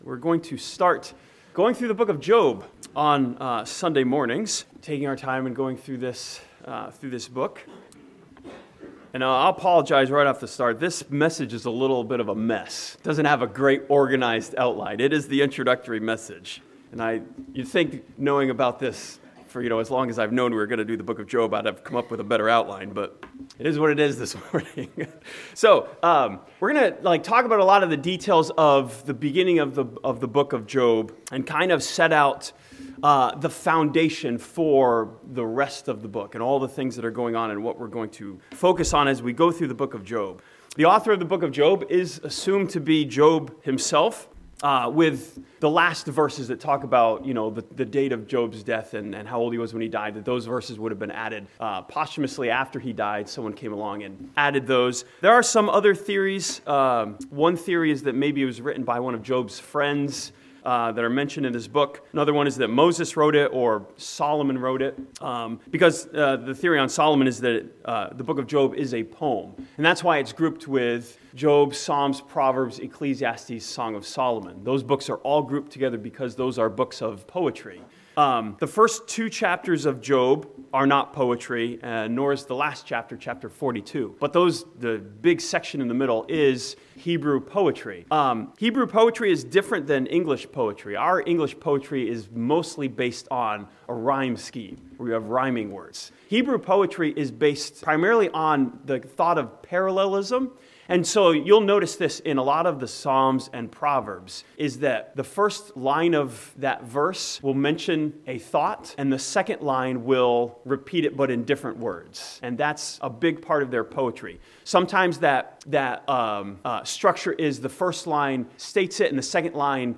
This sermon from the first two chapters of Job begins a news series that finds light in the midst of our darkness.